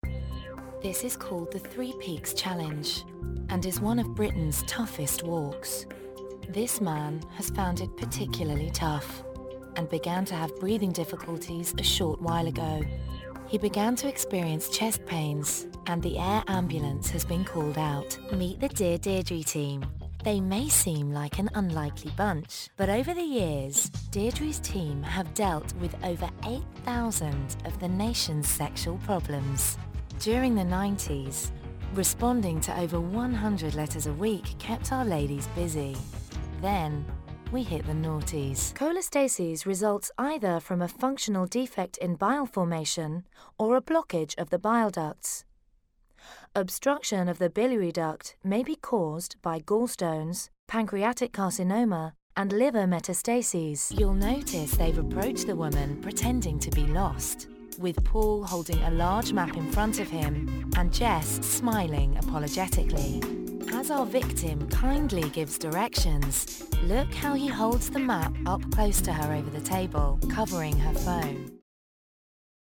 Narration Showreel
Female
Neutral British
Down To Earth
Husky (light)